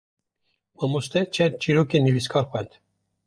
/nɪviːsˈkɑːɾ/